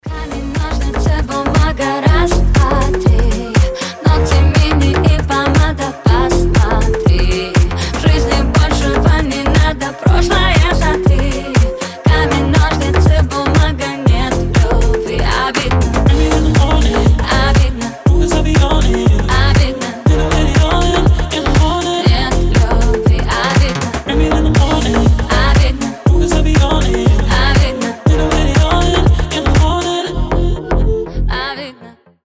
• Качество: 320, Stereo
женский голос
dance
Club House